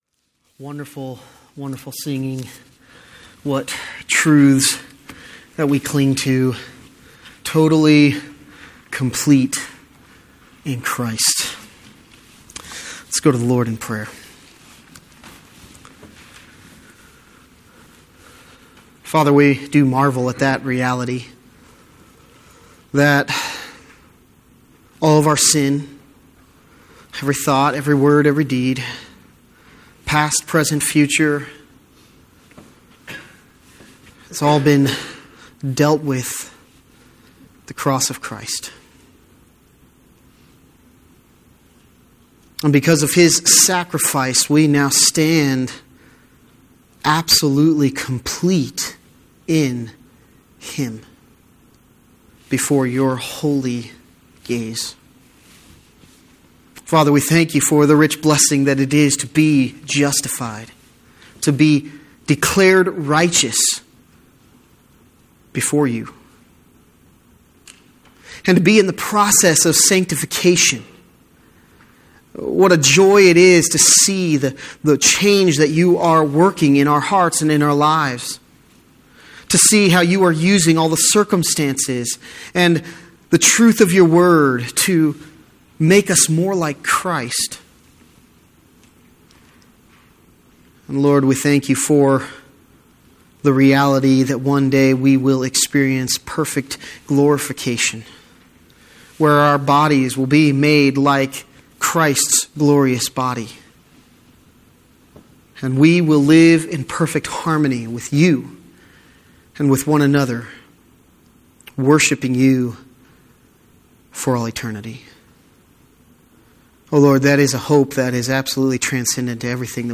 The message on Sunday